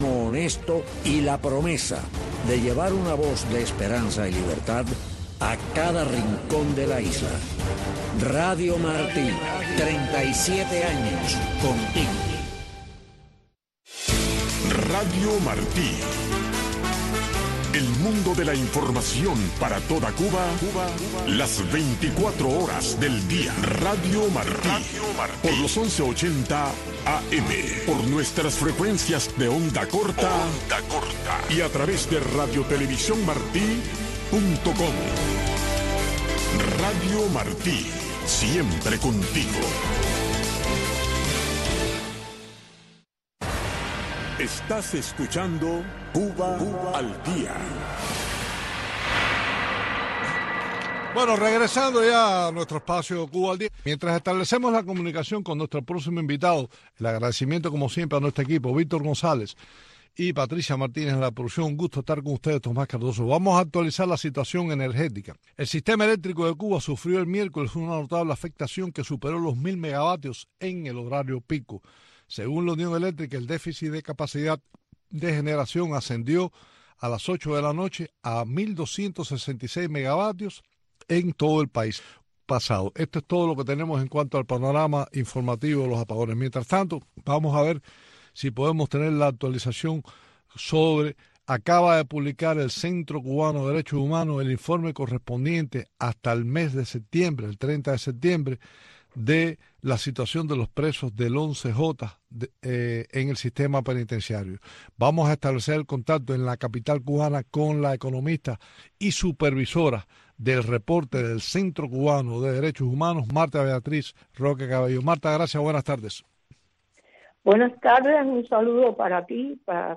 Noticiero de Radio Martí 5:00 PM | Segunda media hora